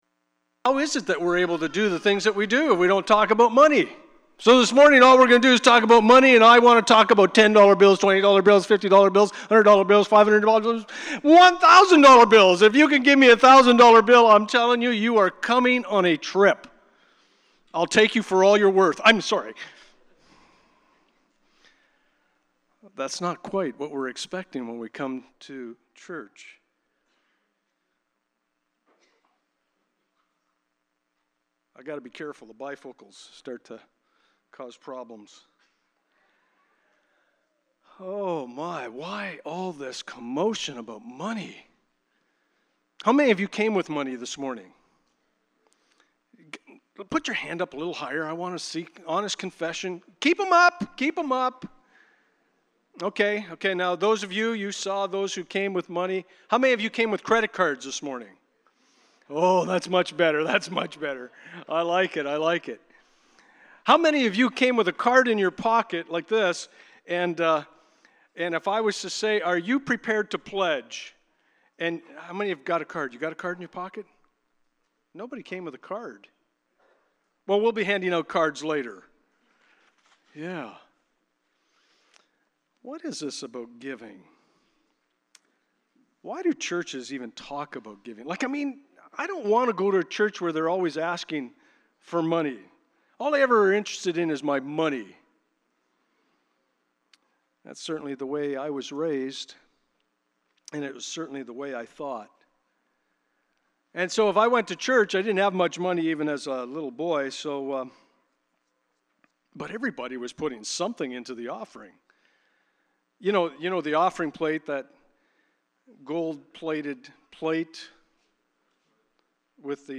2 Corinthians Passage: 2 Corinthians 8:1-12 Service Type: Sunday Morning Please pardon the distortion during the first minute...